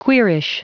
Prononciation du mot queerish en anglais (fichier audio)
Prononciation du mot : queerish